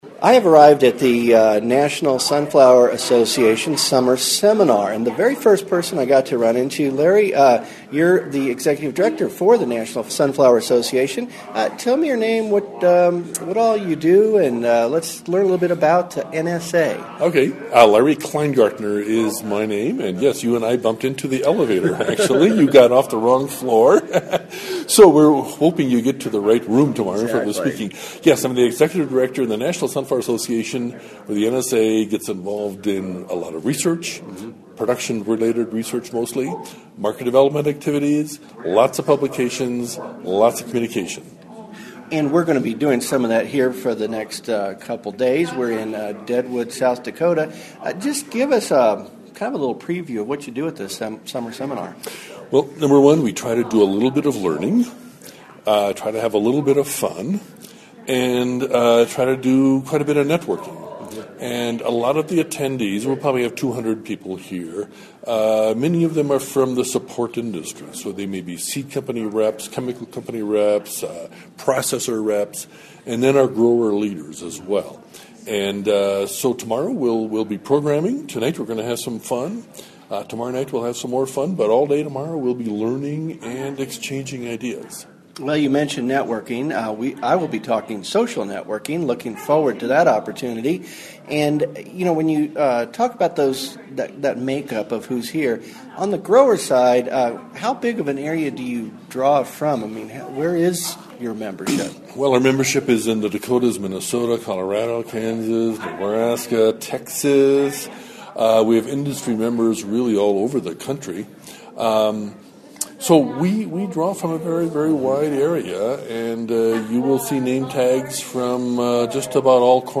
I’m here for the 2011 National Sunflower Association Summer Seminar.
Interview